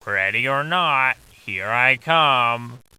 Голос Балди и звуковые эффекты из игры для монтажа видео в mp3 формате